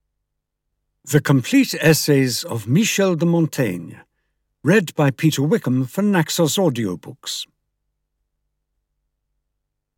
The Complete Essays (EN) audiokniha
Ukázka z knihy